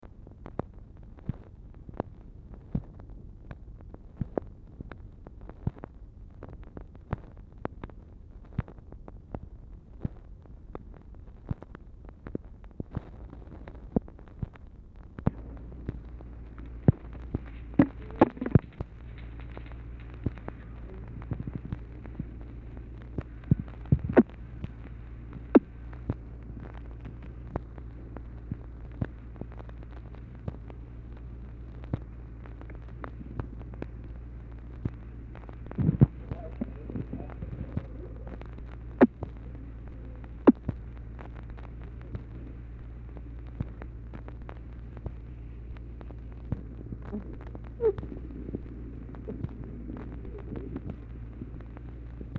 Telephone conversation # 1148, sound recording, OFFICE NOISE, 1/2/1964, time unknown | Discover LBJ
Telephone conversation
Format Dictation belt
LBJ Ranch, near Stonewall, Texas